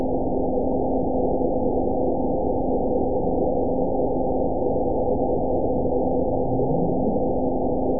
event 920135 date 02/25/24 time 19:34:41 GMT (1 year, 2 months ago) score 8.90 location TSS-AB03 detected by nrw target species NRW annotations +NRW Spectrogram: Frequency (kHz) vs. Time (s) audio not available .wav